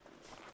Weapon_Foley 08.wav